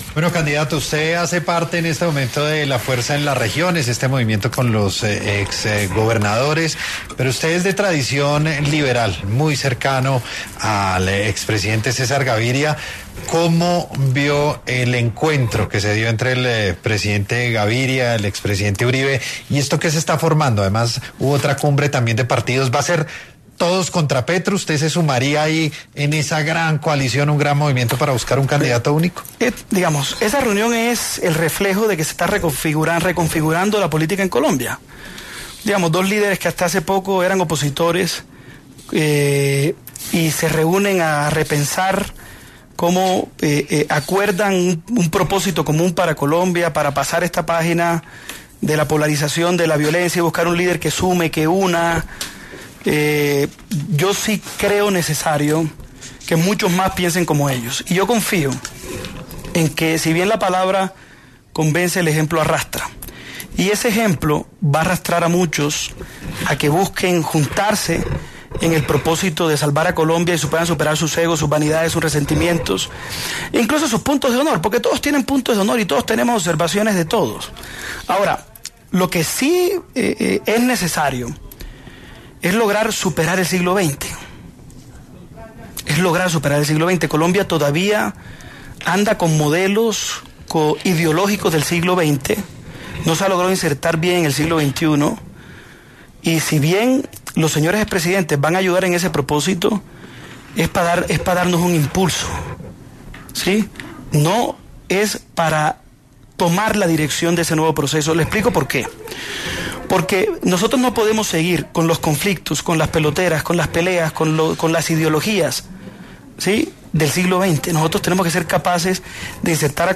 El precandidato presidencial Héctor Olimpo, estuvo en Sin Anestesia de Caracol Radio hablando sobre la actualidad en la política de Colombia